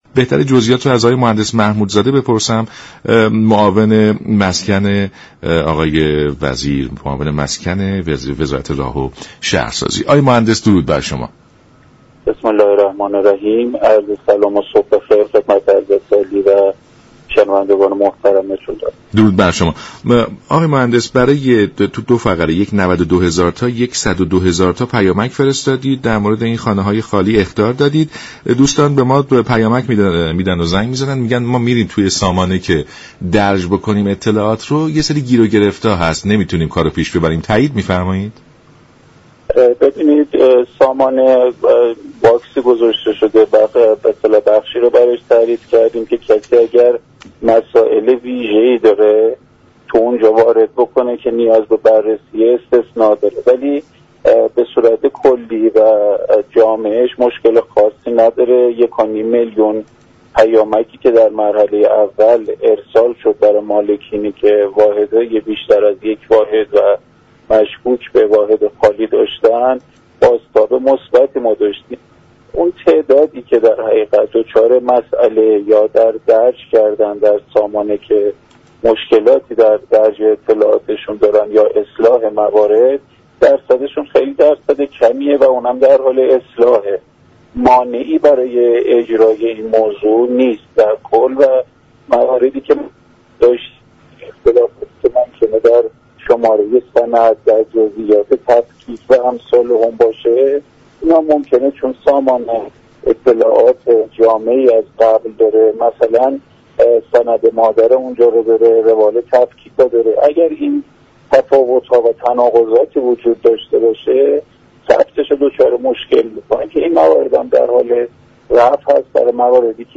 به گزارش شبكه رادیویی ایران، محمود محمودزاده معاون مسكن و ساختمان وزارت راه و شهرسازی در برنامه سلام صبح بخیر رادیو ایران با بیان اینكه تاكنون در دو مرحله برای مالكان واحدهای خالی از سكنه پیامك ارسال شده است، گفت: در مرحله اول به 92 هزار مالك و در مرحله دوم به 102 هزار مالك پیامك ارسال شده است.